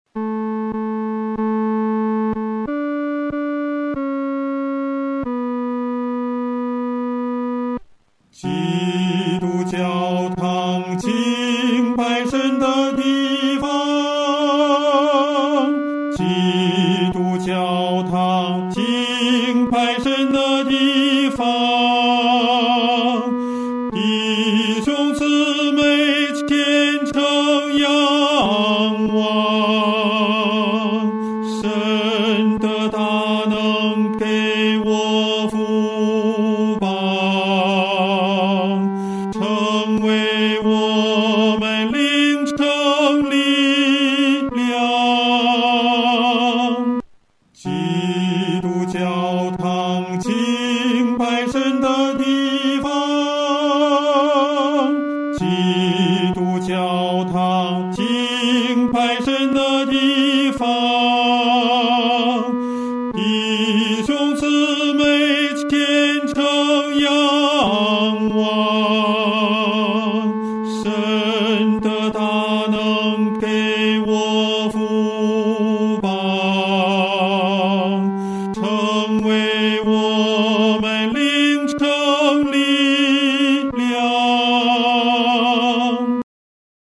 男高
这首诗歌宜用不快的中速来弹唱，声音要饱满。